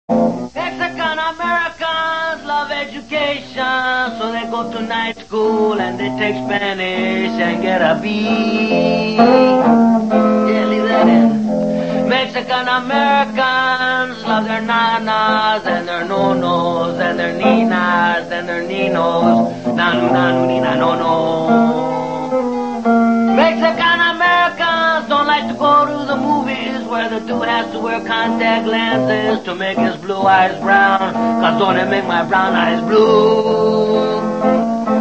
• Funny Ringtones